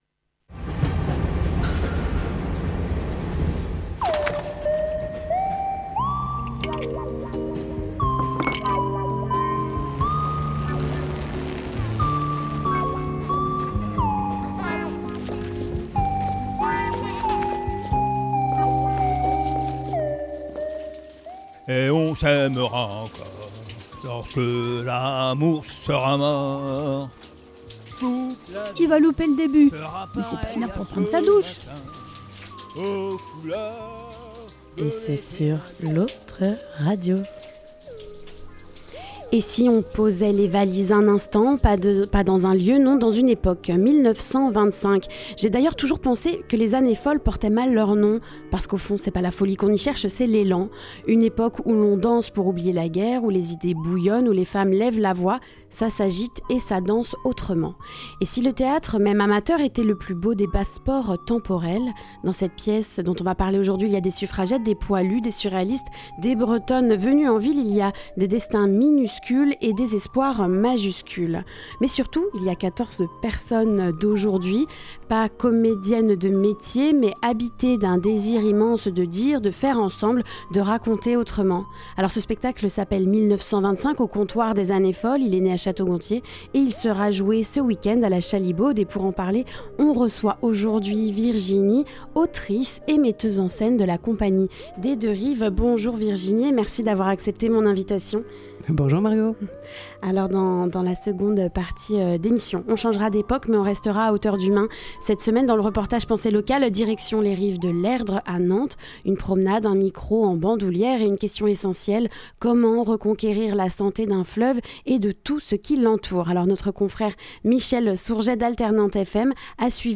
Le reportage Penser Local : Améliorer la qualité des milieux aquatiques de l’Erdre, une approche globale